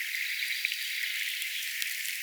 peippo laulaa säkeen
vähän kuin hernekertun nuotilla?
mika_laji_onko_varmasti_peippo_vahan_hernekerttumainen_savel.mp3